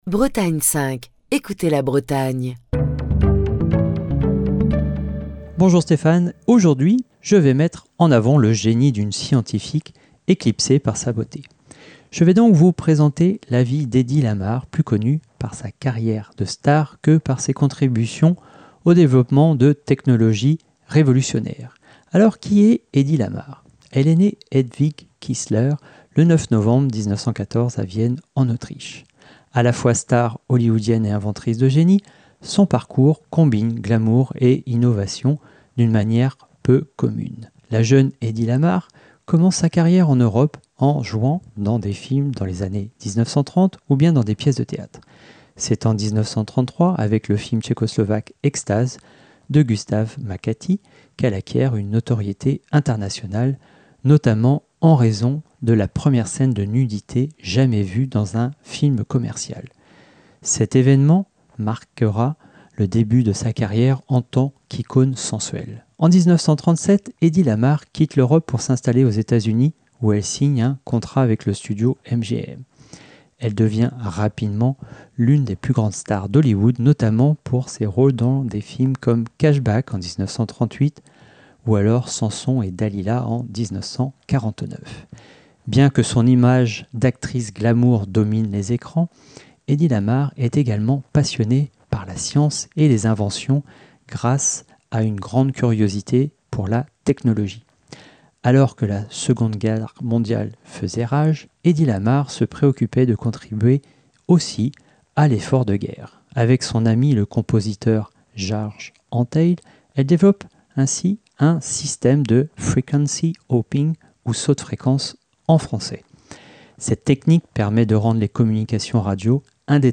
Chronique du 26 février 2025.